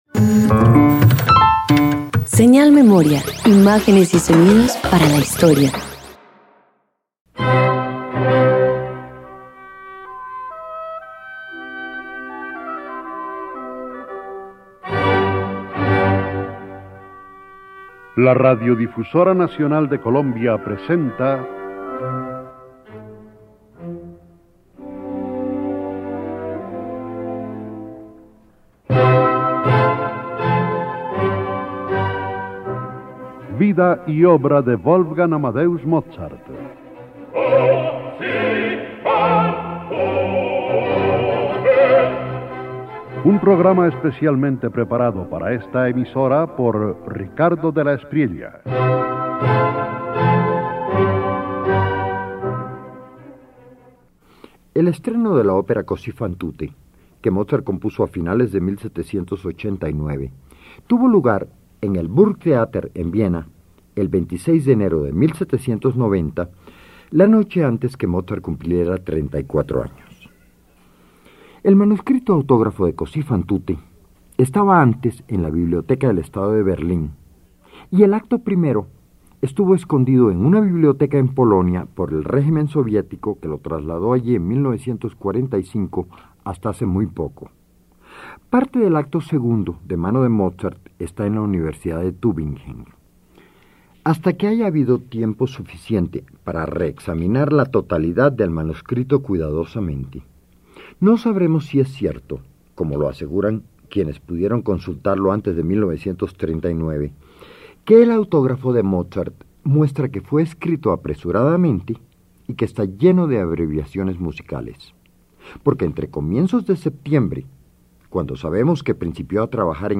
306 Opera Cosi Fan Tutte  Parte II_1.mp3